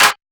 SOUTHSIDE_snare_layers.wav